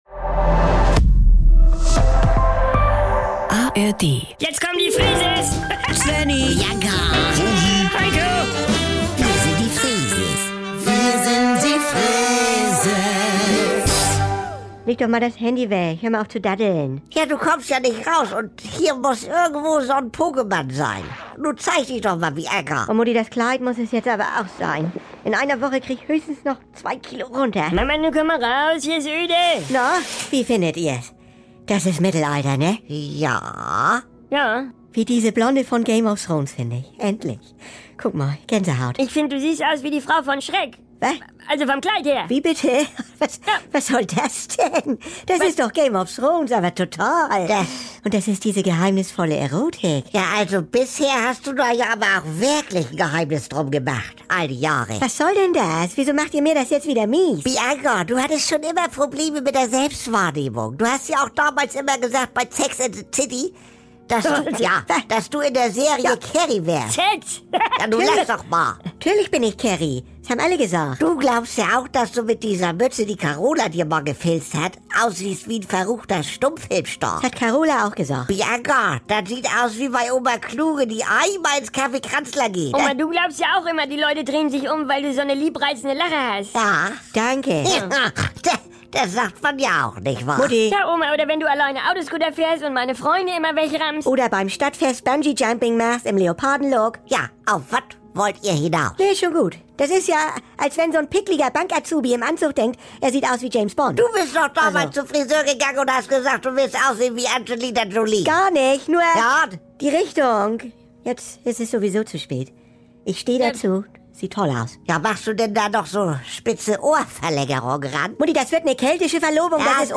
und so oft ihr wollt: Die NDR 2 Kult-Comedy direkt aus dem